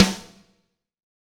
Q2 S-SN rim.WAV